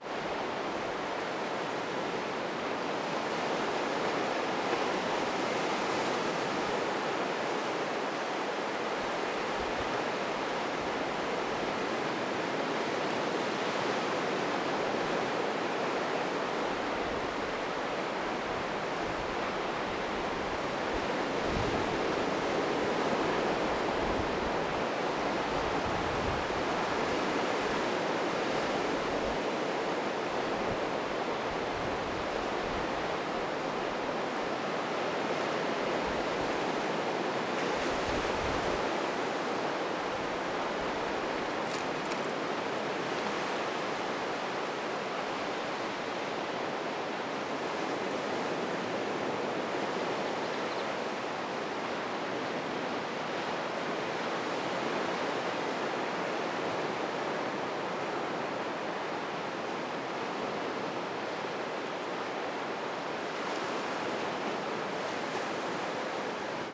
Cliff top